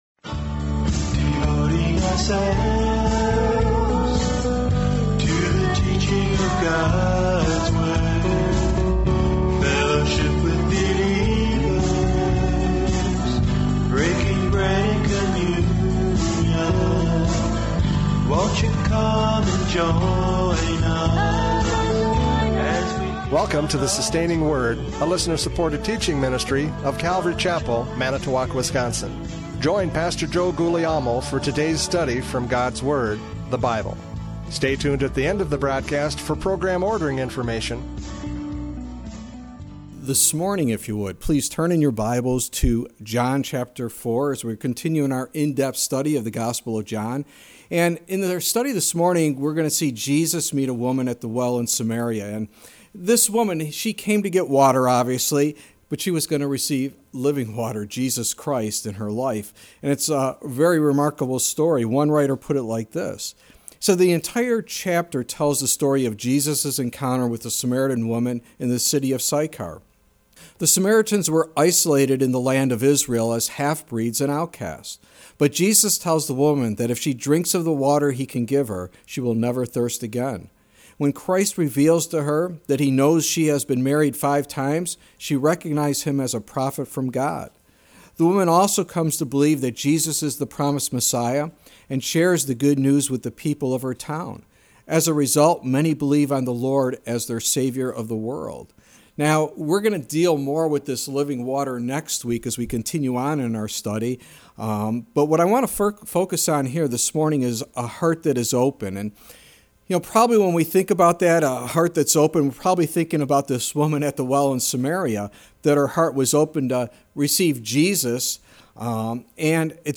John 4:1-10 Service Type: Radio Programs « John 3:22-36 Absolute Surrender!